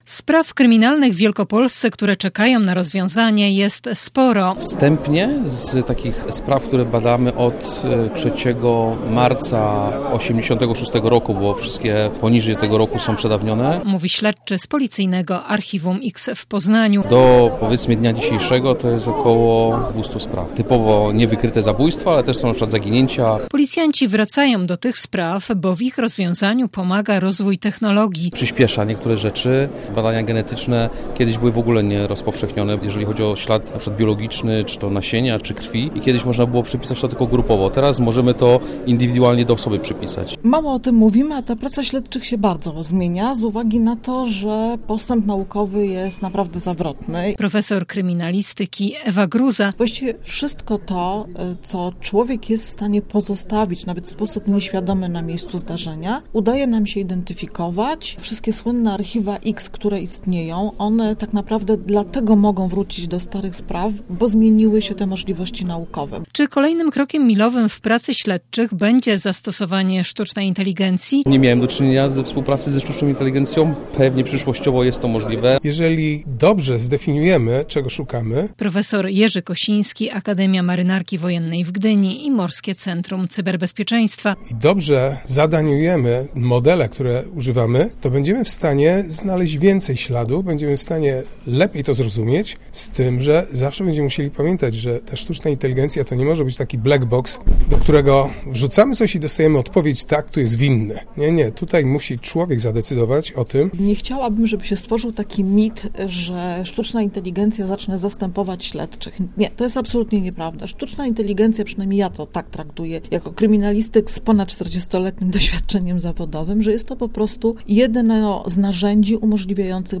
Z tym pytaniem mierzą się uczestnicy pierwszej Konferencji Kryminalistycznej, która w czwartek rozpoczęła się w Poznaniu.